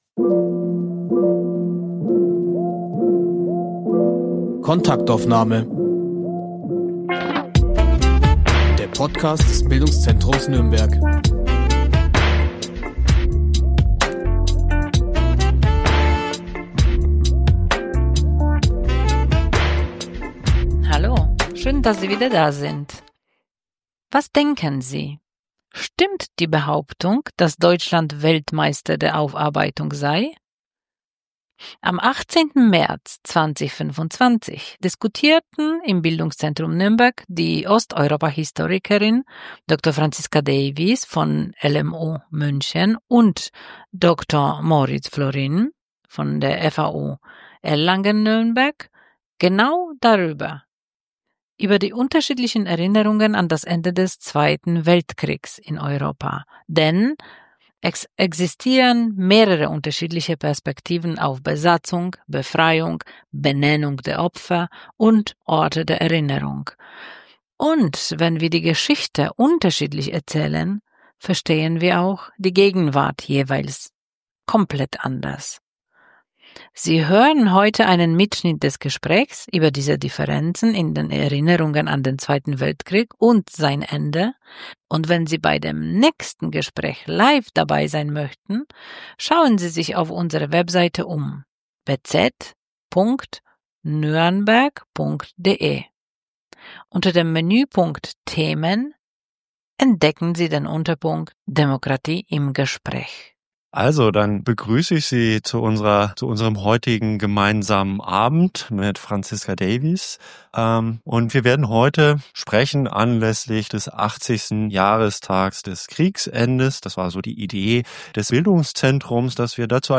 Mitschnitt einer Liveveranstaltung